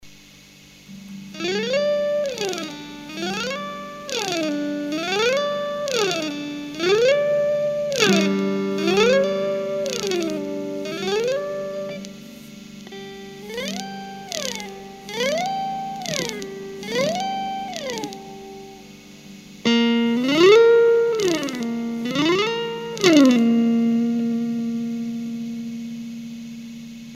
Damit meine ich nicht das Knacken bei Berührung der Saite;sondern bei gegriffener Saite und Berührung des Fingers eines Bundstabs!